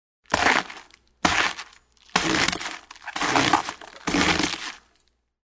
Звуки трещины
Деревянный предмет разломали